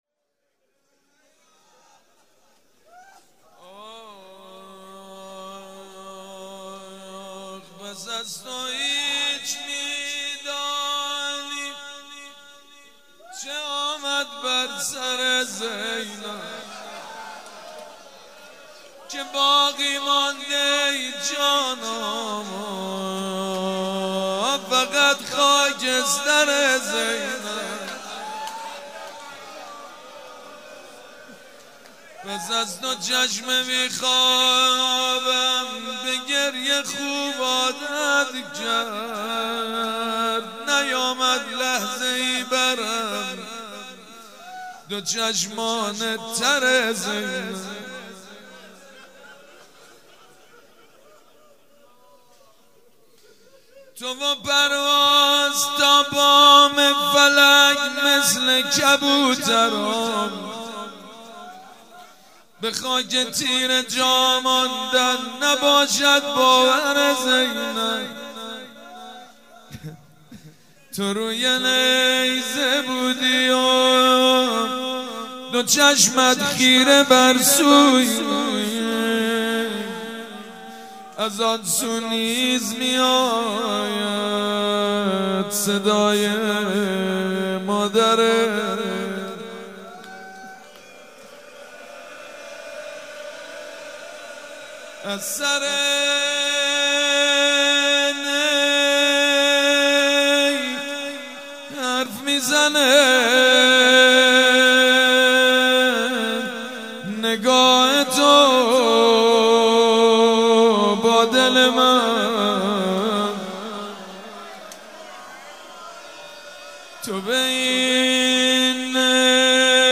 روضه شب اول مراسم عزاداری صفر
روضه
مداح